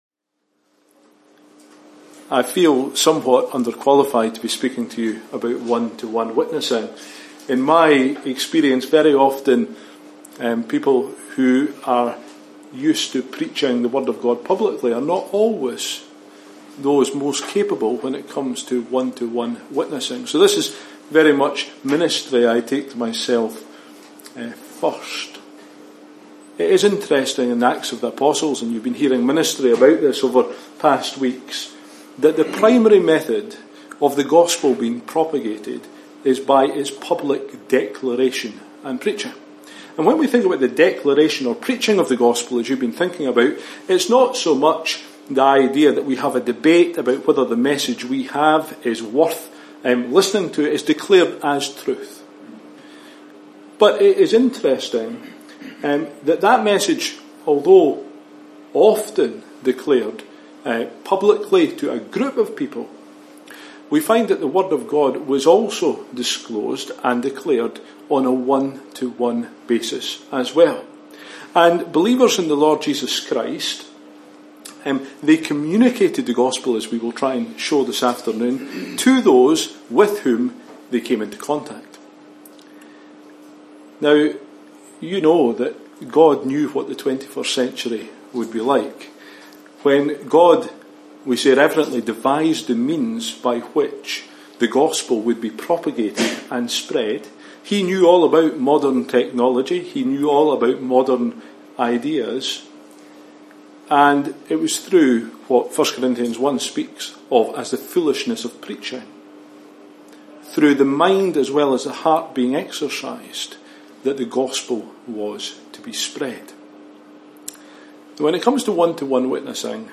We should be personally bringing the gospel into our street, our workplace and our circle of friends and contacts. We can do this by using opportunities (Acts 11:19-21), using hospitality (Acts 18:24-28) and using “prophecy” (Acts 8:26-39). In doing so we should be prayerful, intentional and walking in fellowship with the Lord – so that we might be effective channels of the gospel to others (Message preached 13th Oct 2019)